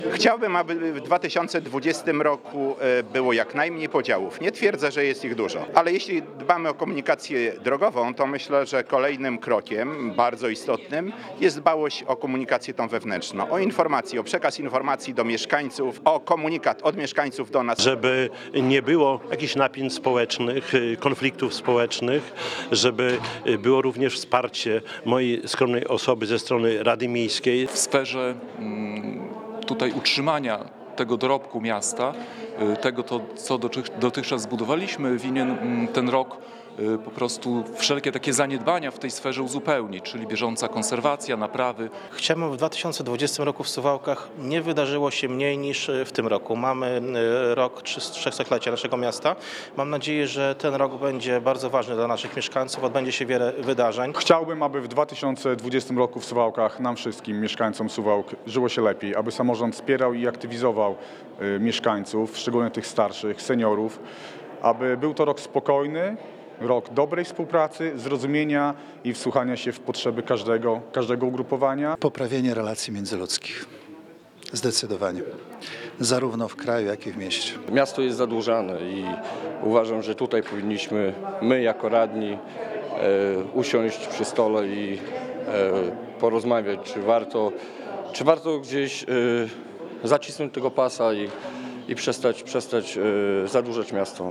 Dziś (30.12)  podczas ostatniej w tym roku sesji Rady Miejskiej z kamerą i mikrofonem pytaliśmy samorządowców, co było najważniejsze w mijającym roku. Najczęściej pojawiającą się odpowiedzią było zakończenie budowy obwodnicy Suwałk.
Również obwodnicę i budowę hali wymienia Czesław Renkiewicz, prezydent Suwałk.